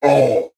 ogre3.wav